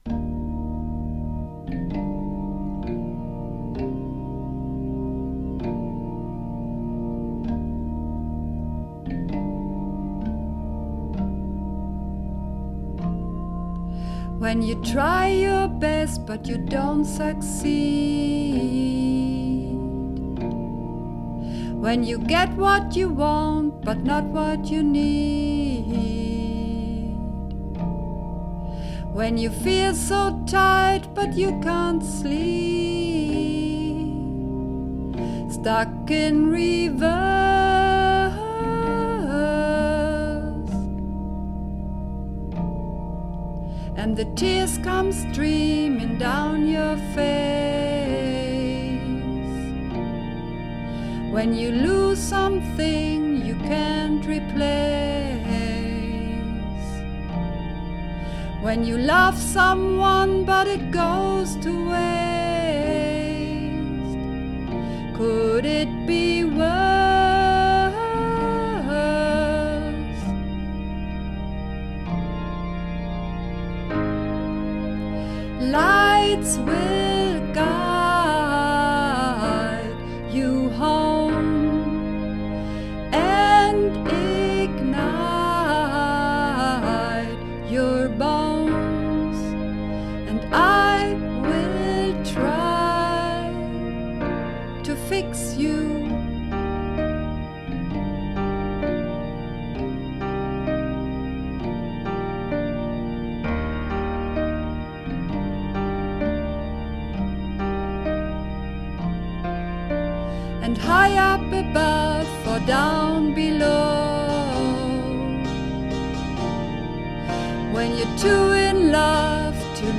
Übungsaufnahmen
Fix You (Sopran)
Fix_You__3_Sopran.mp3